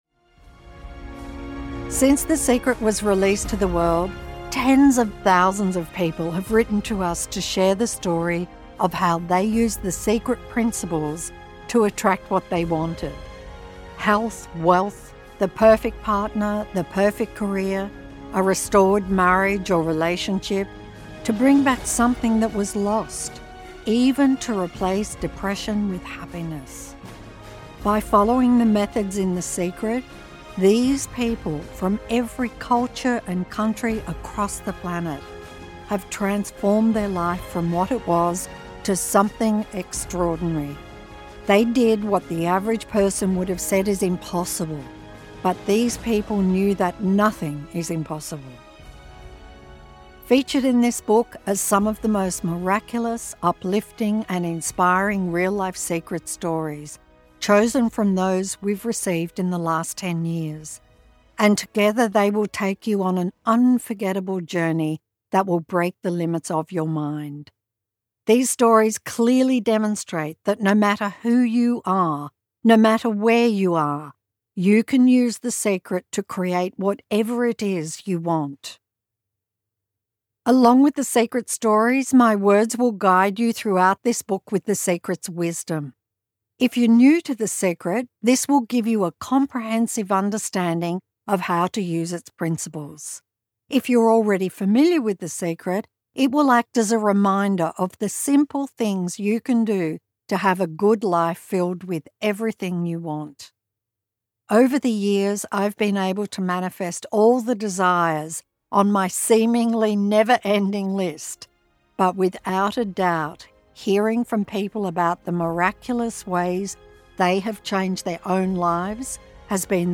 Official website of The Secret, How The Secret Changed My Life, Audiobook CD and The Secret Book Series by Rhonda Byrne.
Read by the Author, Rhonda Byrne